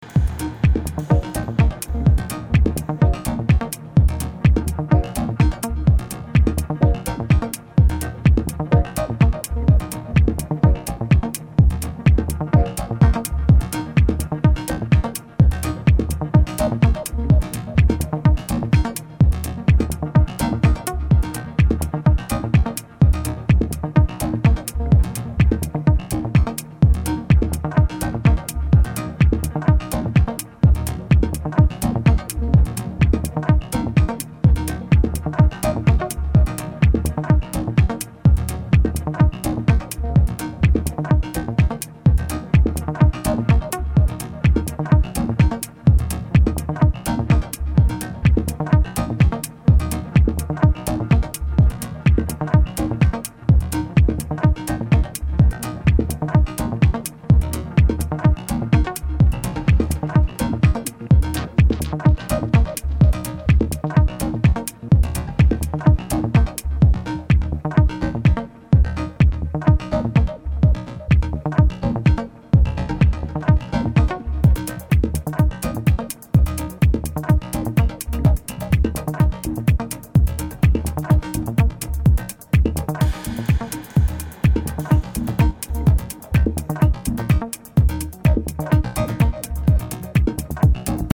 Techno Acid